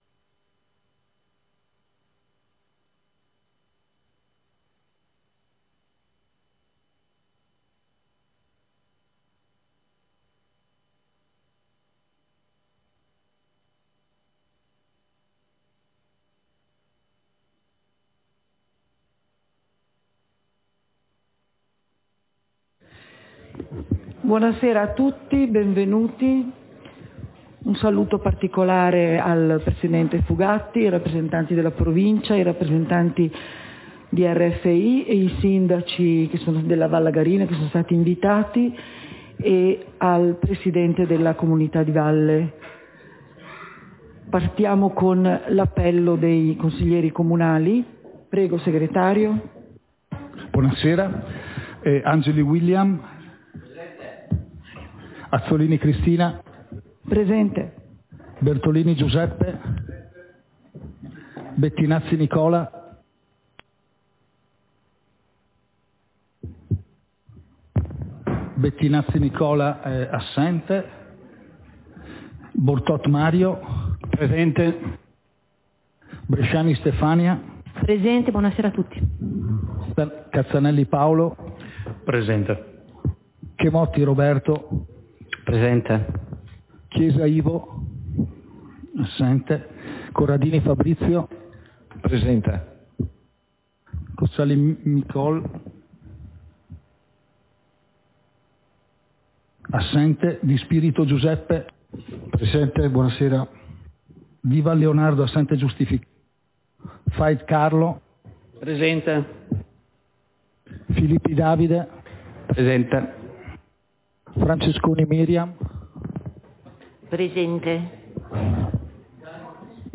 Seduta del consiglio comunale - 14.03.2024